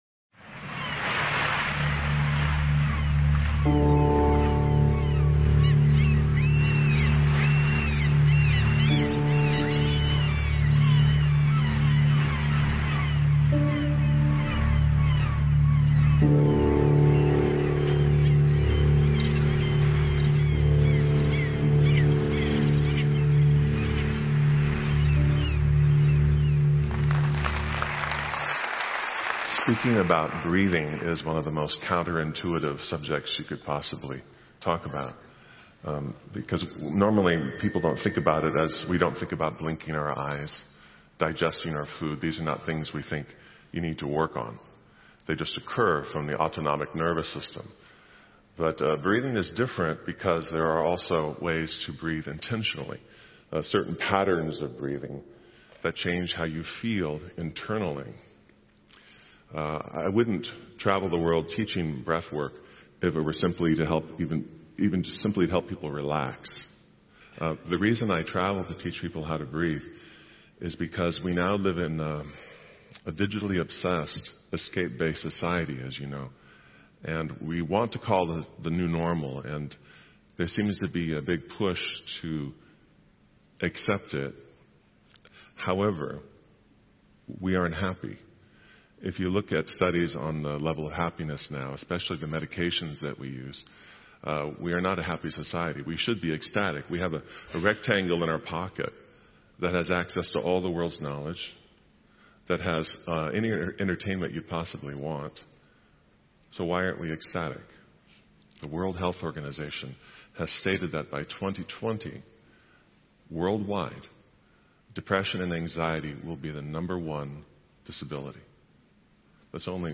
TEDx Talk Presentation